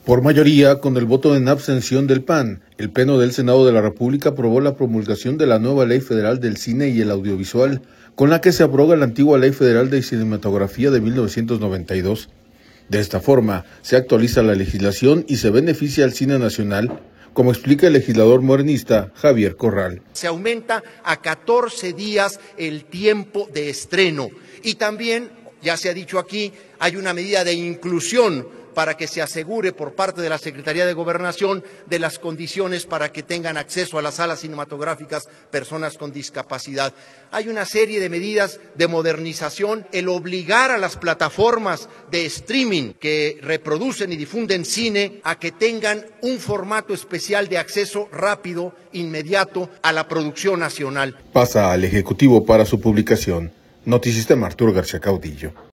audio Por mayoría, con el voto en abstención del PAN, el Pleno del Senado de la República aprobó la promulgación de la nueva Ley Federal del Cine y el Audiovisual con la que se abroga la antigua Ley Federal de Cinematografía de 1992. De esta forma de actualiza la legislación y se beneficia al cine nacional, como explica el legislador morenista Javier Corral.